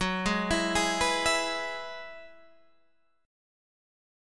Gb7sus2sus4 chord